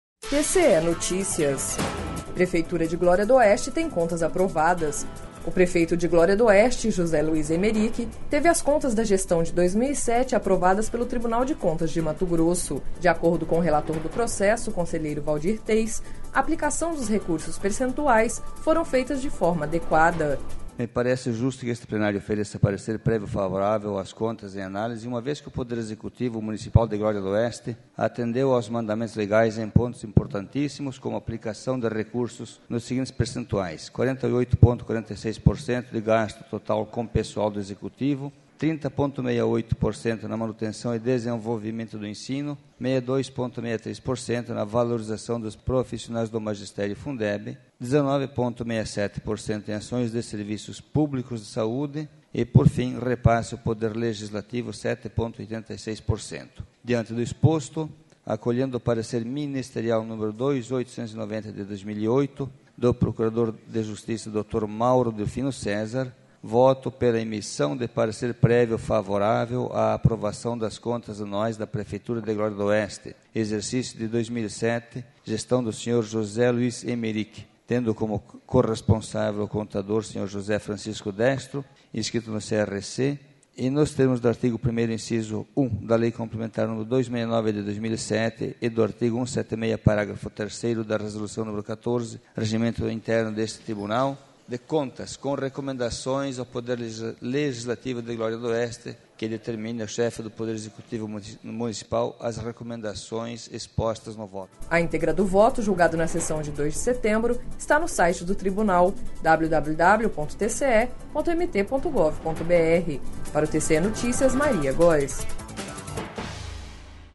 Sonora: Waldir Teis – conselheiro do TCE